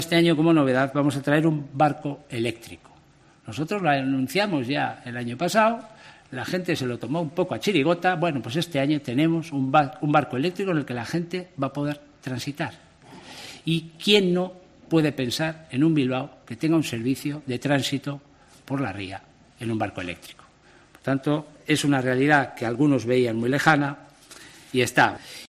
Alfonso Gil, concejal bilbaíno de movilidad